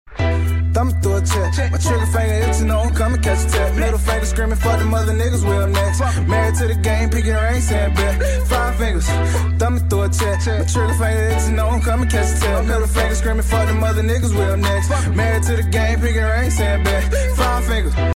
Hip-Hop Ringtones